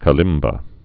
(kə-lĭmbə)